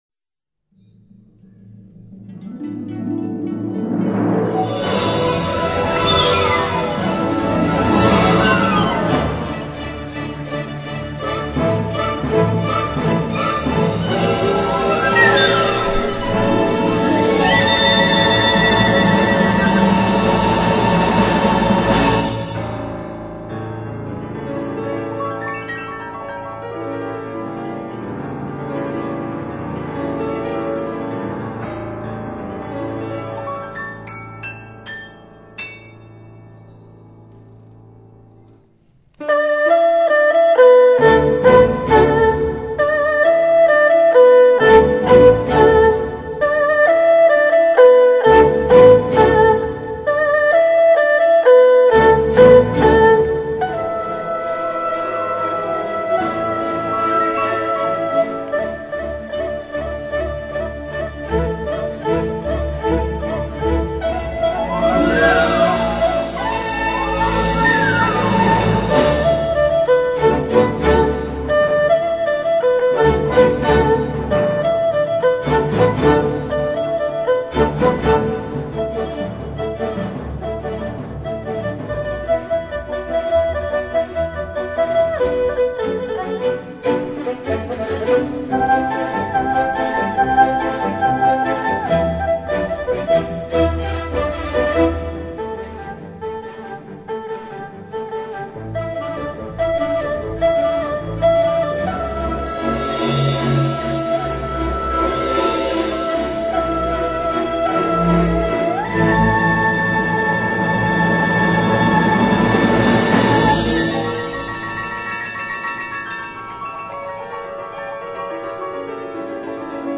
二胡协奏曲(二乐章欣赏)